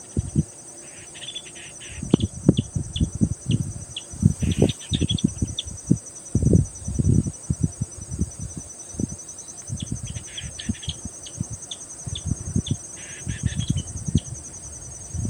Yellow-breasted Crake (Laterallus flaviventer)
Life Stage: Adult
Detailed location: Lago Inia Salto Grande
Condition: Wild
Certainty: Recorded vocal